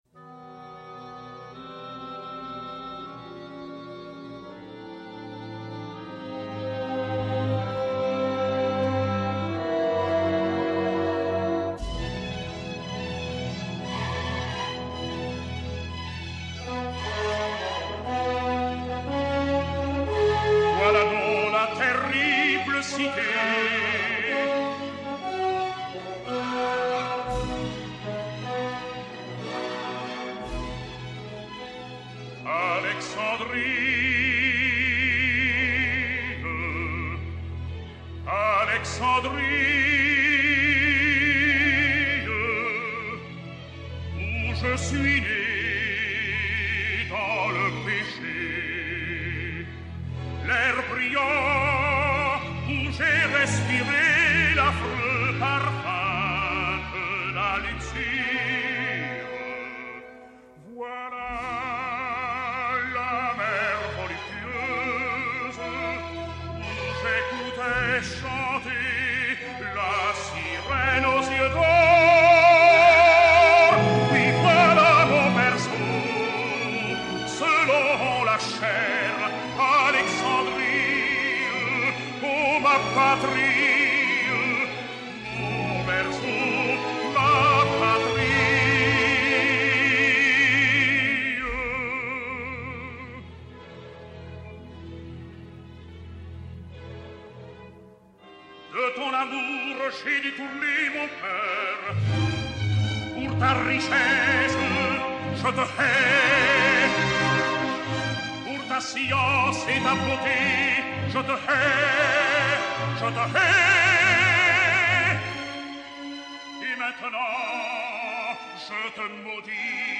enr. à Paris en avril/mai 1961